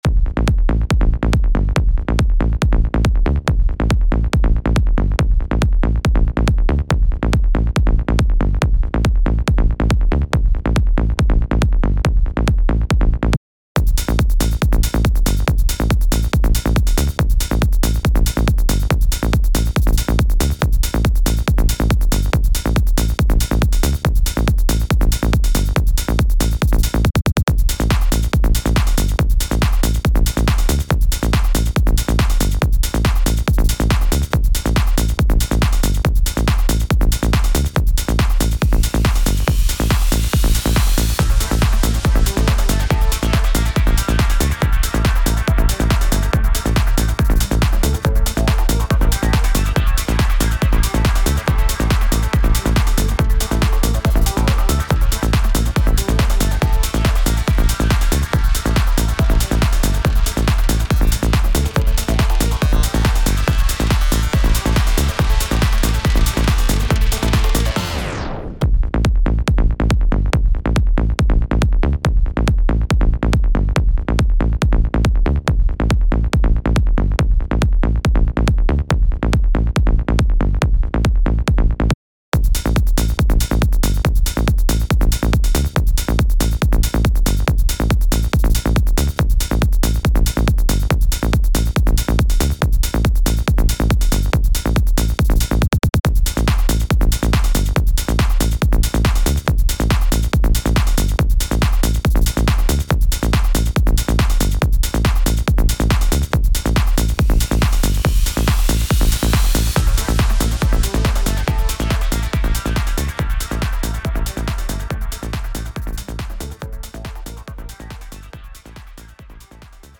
タグ: Beat EDM Trance 電子音楽 コメント: ミニマルなトランス系のトラック。